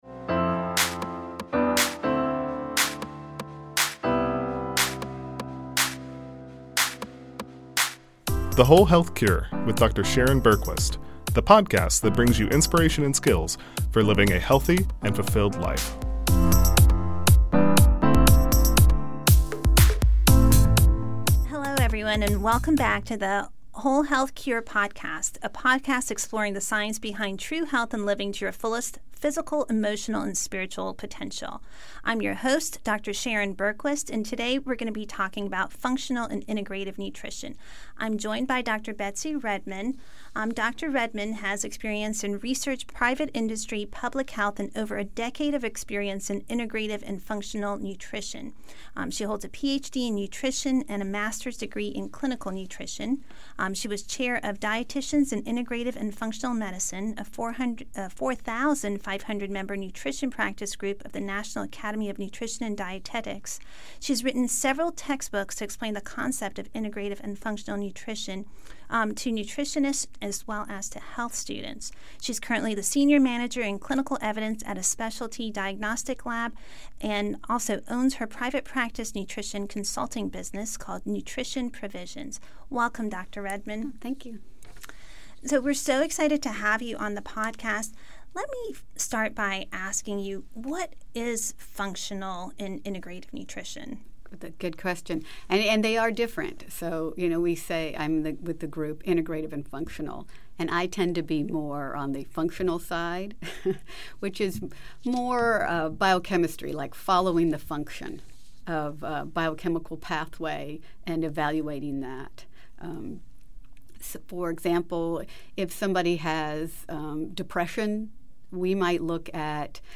This is a conversation about functional and integrative nutrition, various testing options available on the market today, common nutrient deficiencies , and how and when to look for dietitian.